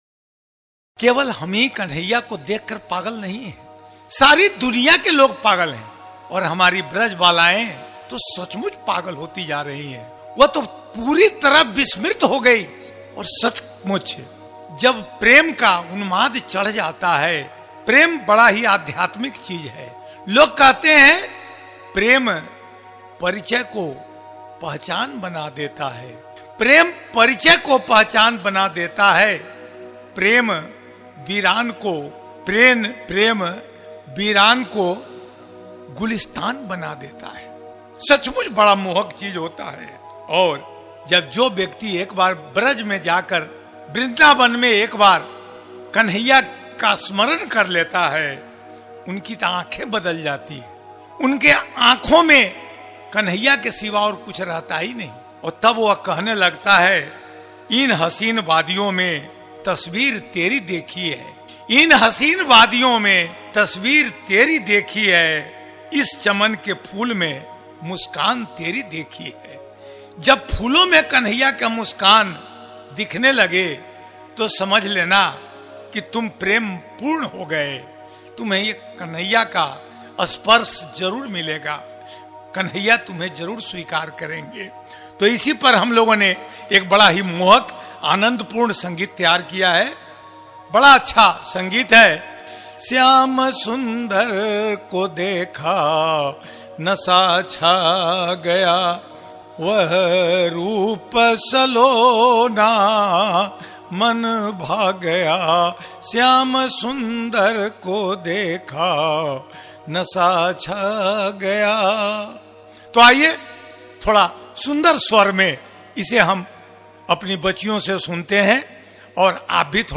भक्ति संगीतों